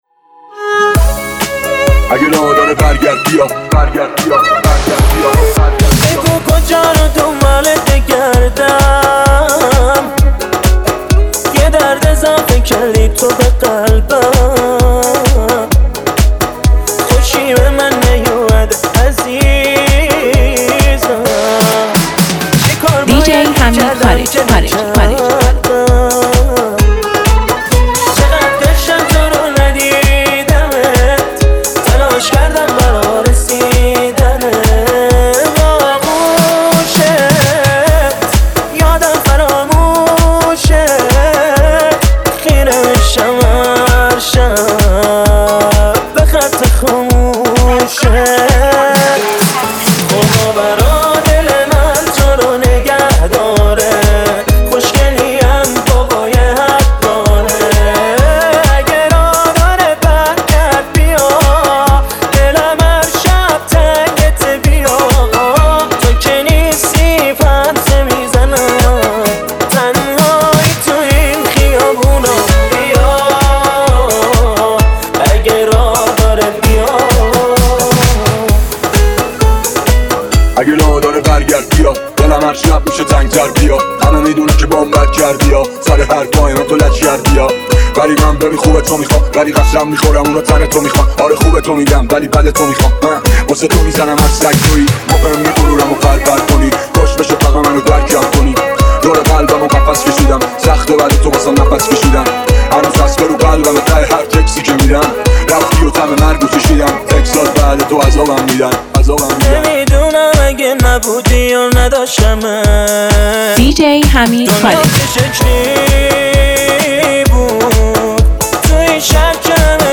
ریمیکس اینستا